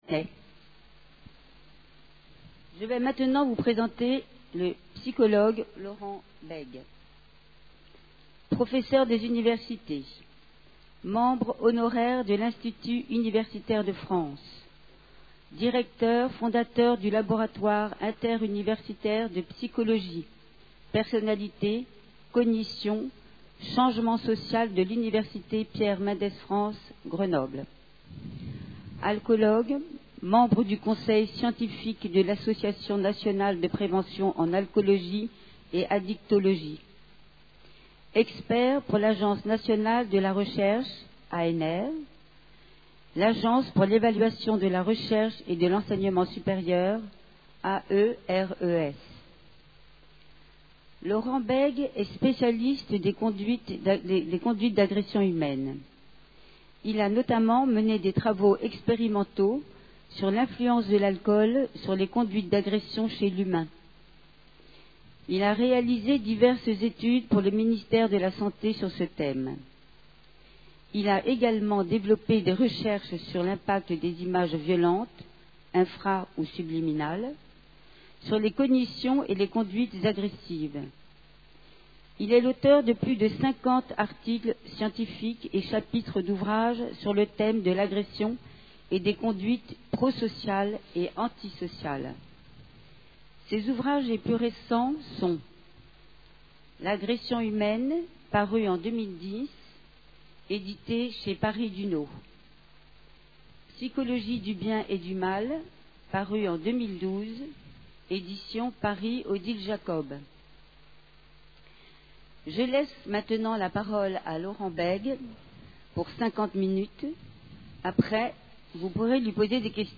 Une conférence du cycle "La violence aujourd'hui" Alcool